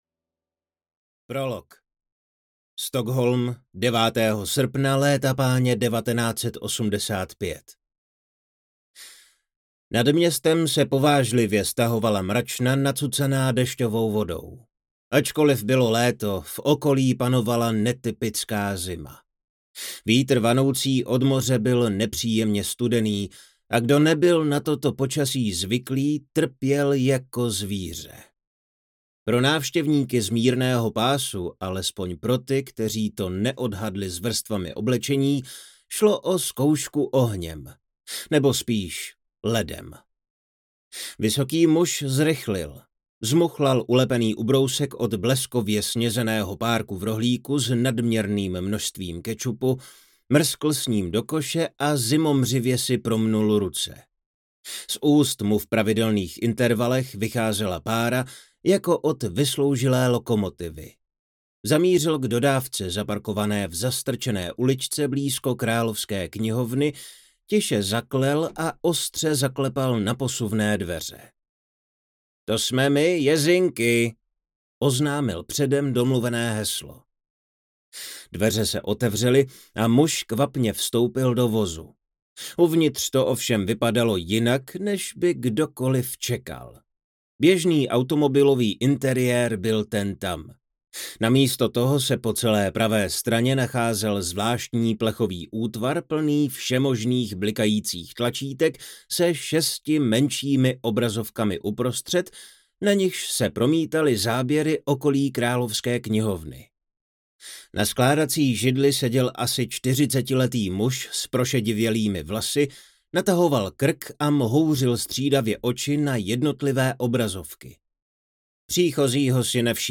Dědictví zkázy audiokniha
Ukázka z knihy